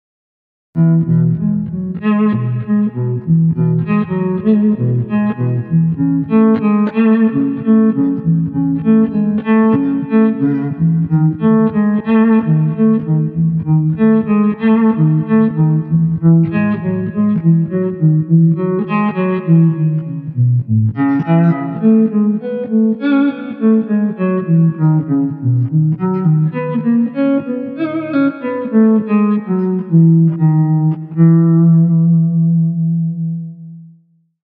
• Adjustable Swell function creates bowed, fade in and reverse effects
Using Swell for Bowed Effect
PlatformStereo-Compressor-Limiter-Using-Swell-for-Bowed-Effect.mp3